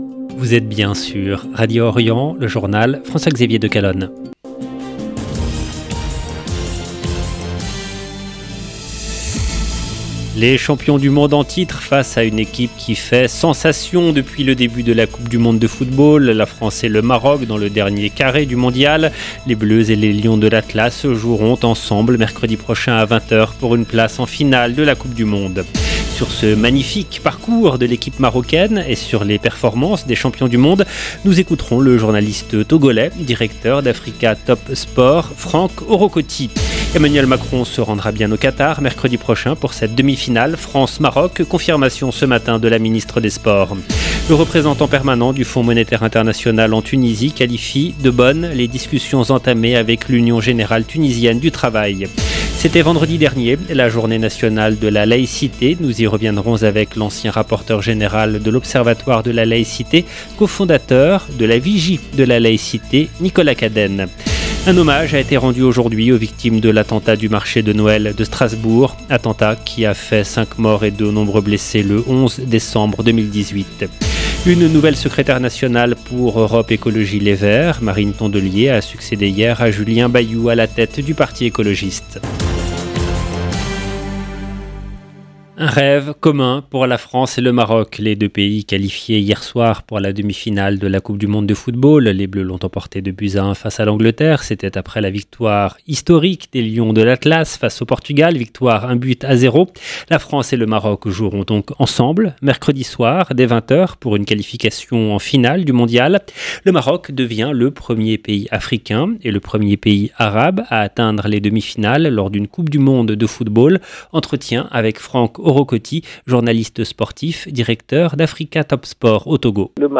EDITION DU JOURNAL DU SOIR EN LANGUE FRANCAISE DU 11/12/2022